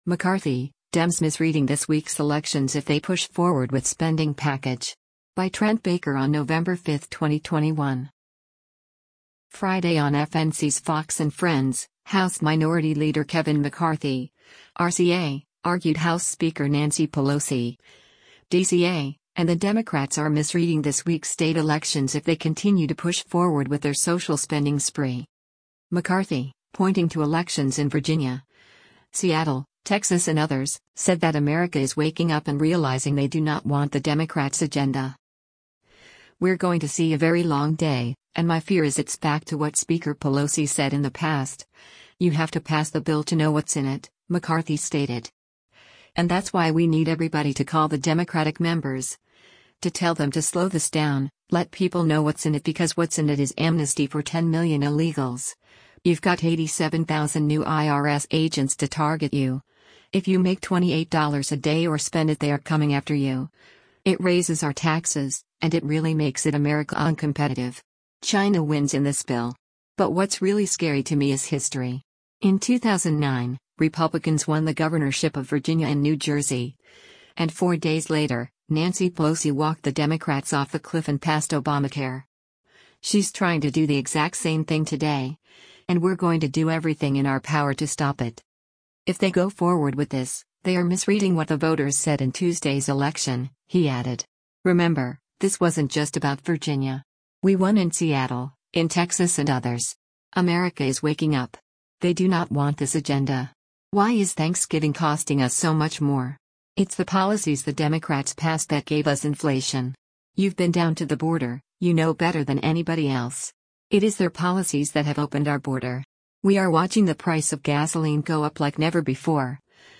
Friday on FNC’s “Fox & Friends,” House Minority Leader Kevin McCarthy (R-CA) argued House Speaker Nancy Pelosi (D-CA) and the Democrats are “misreading” this week’s state elections if they continue to push forward with their social spending spree.